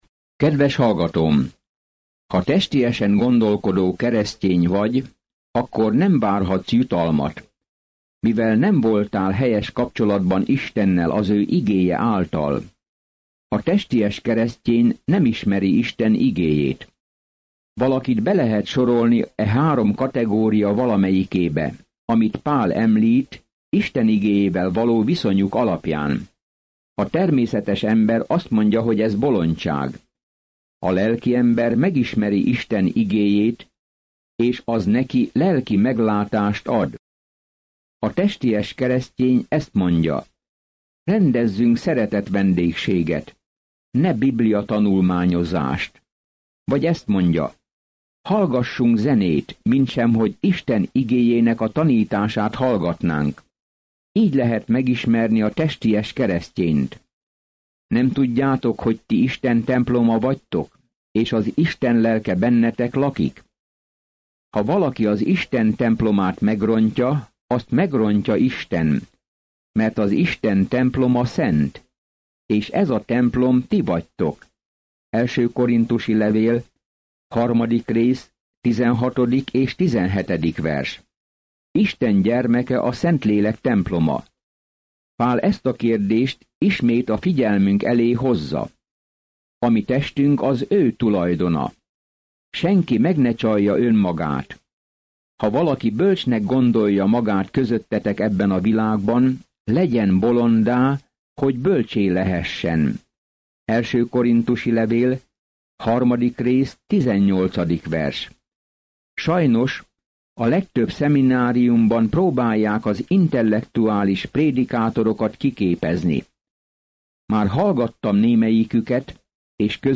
Napi utazás az 1. korinthusi levélben, miközben hallgatja a hangos tanulmányt, és olvassa kiválasztott verseket Isten szavából.